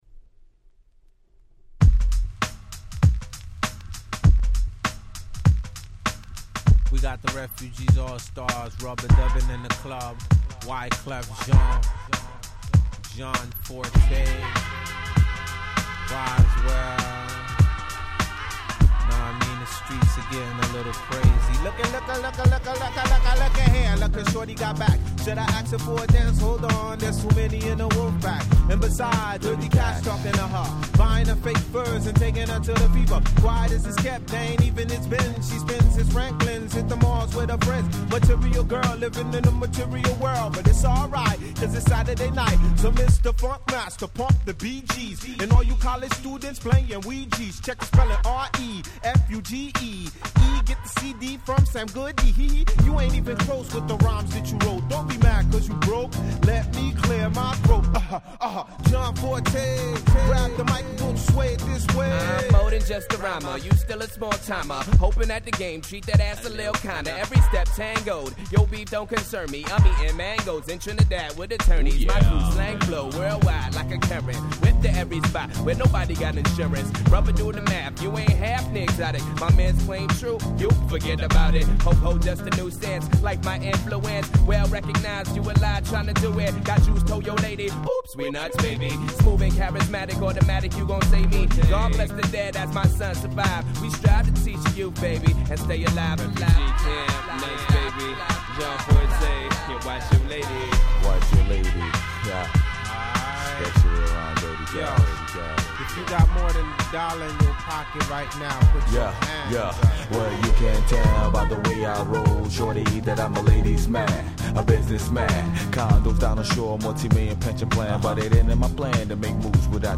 97' Super Hit Hip Hop !!
Disco ディスコ 90's